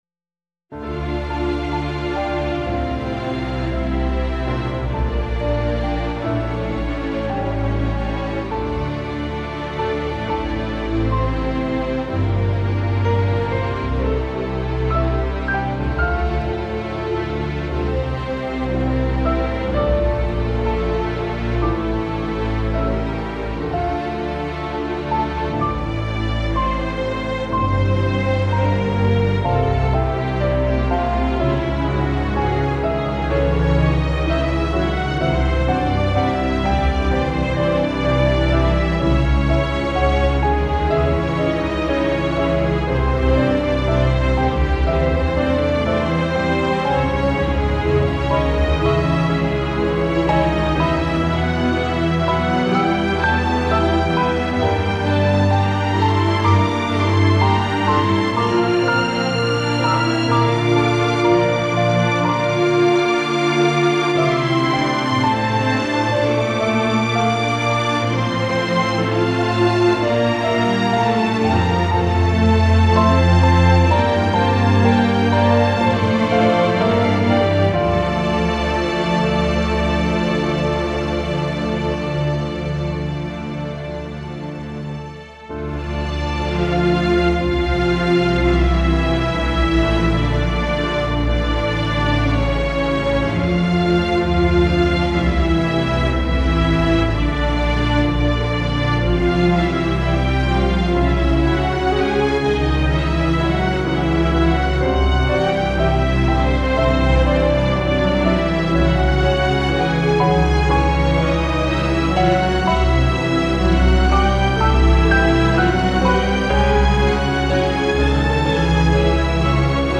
aerien - nostalgique - calme - melancolie - piano